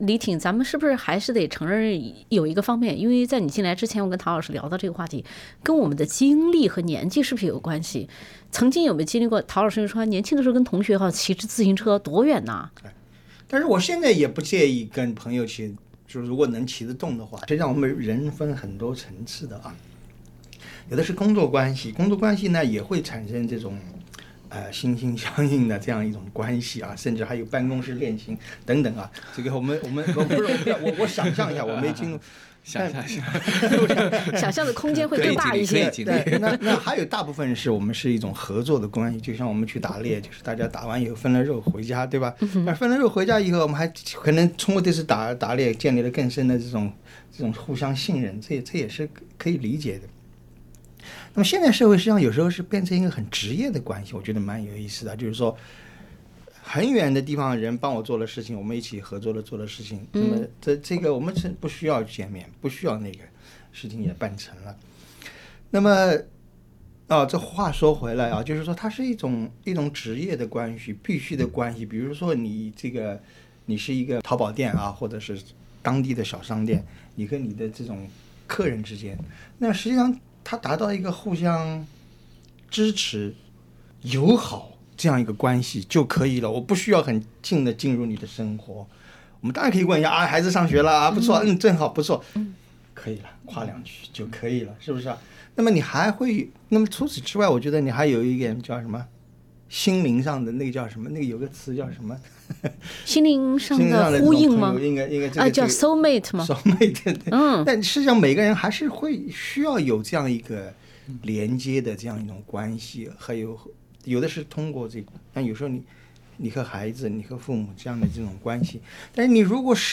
欢迎收听SBS 文化时评栏目《文化苦丁茶》，本期话题是：宅着？丧着？佛系着（全集）。